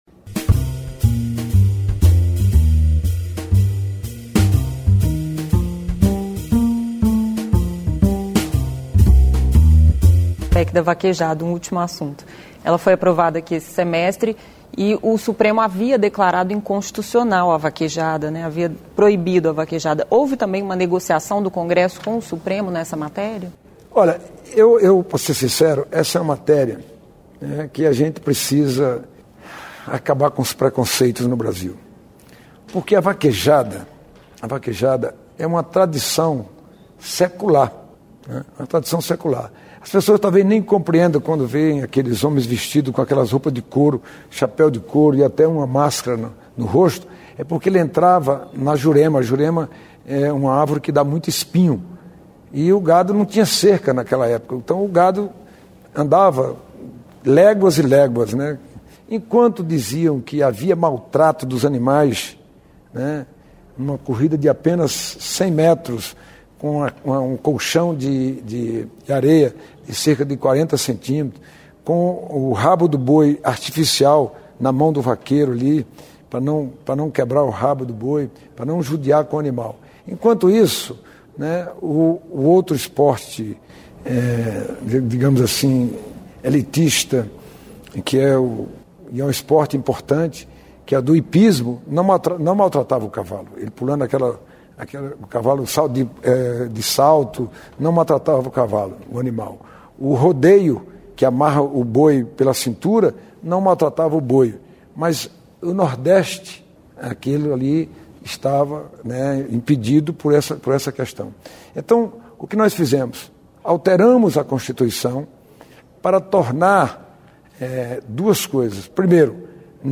O presidente do Senado, Eunício Oliveira, foi o primeiro convidado do programa Salão Nobre, que estreou na TV Senado na última quinta-feira (28).
Salão Nobre